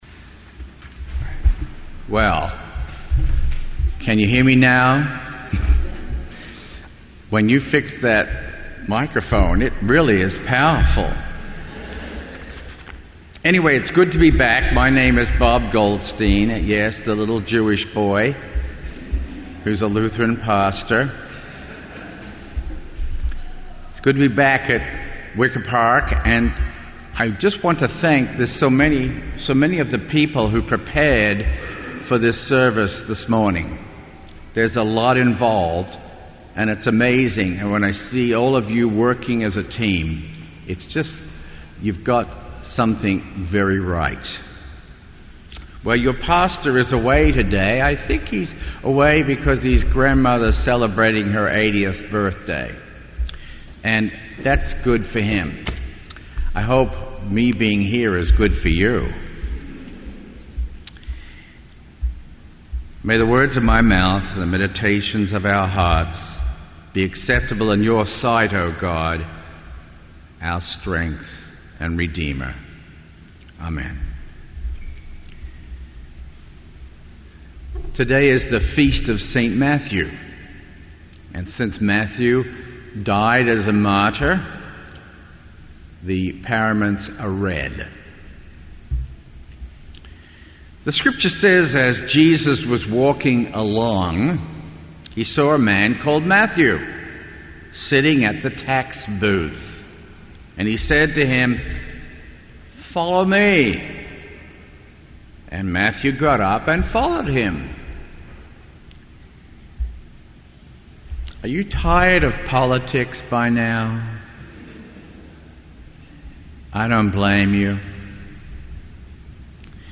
Sermon_9_20_15.mp3